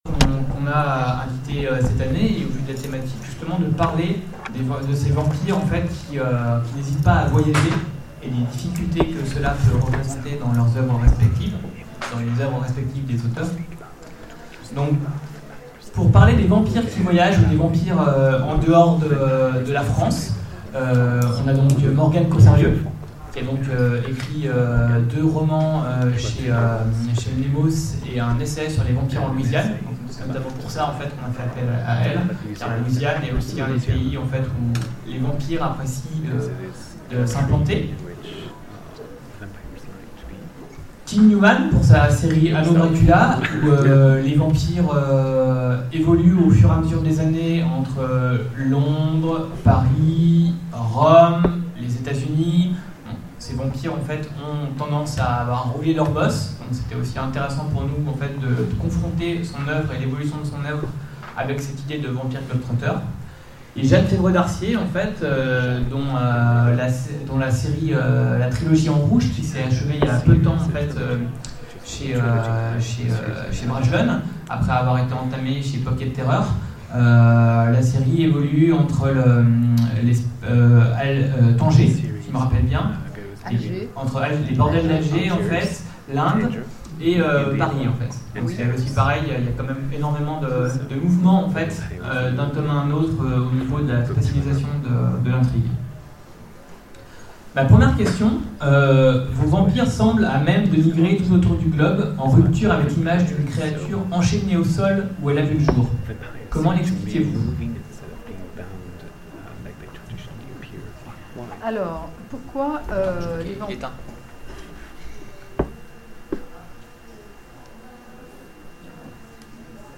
Salon du vampire 2016 : Conférence Le vampire ce globe-trotter